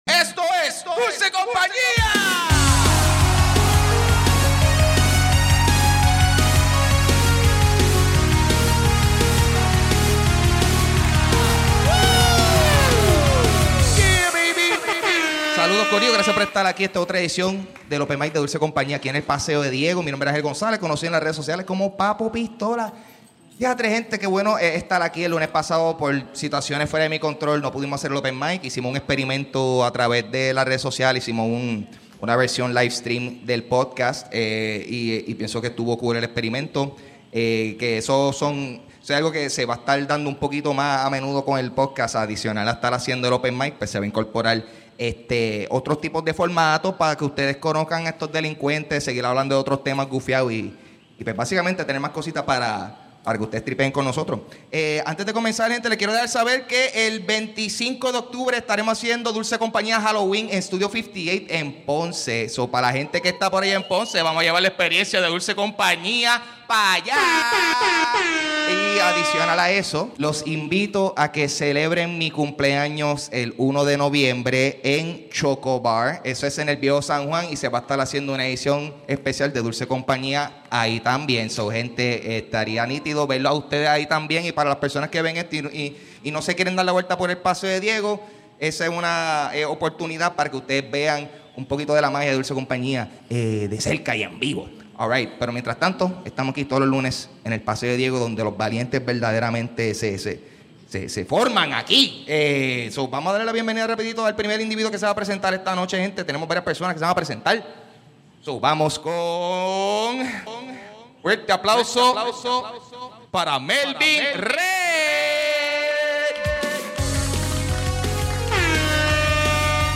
Regresamos con el open mic y descubrimos que uno de los comediantes aparentemente puede comunicarse con delfines.